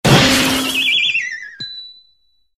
metal_joint_break_02.ogg